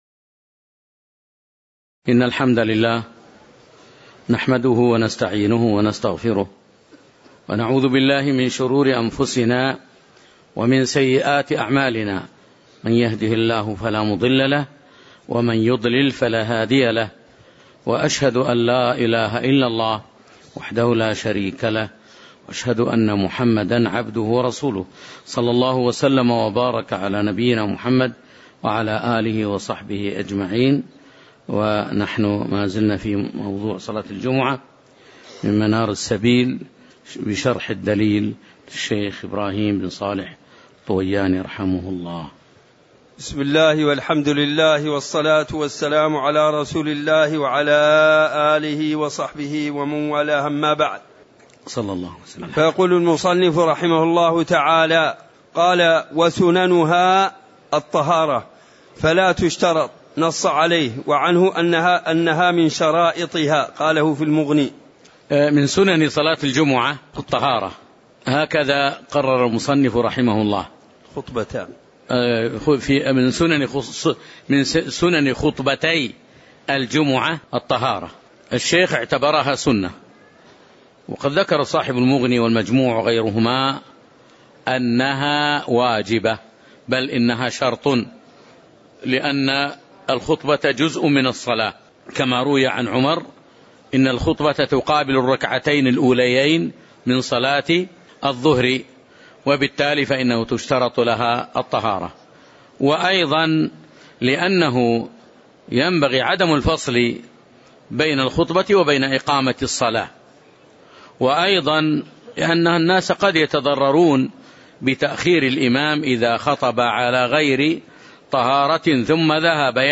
تاريخ النشر ٦ ربيع الأول ١٤٣٩ هـ المكان: المسجد النبوي الشيخ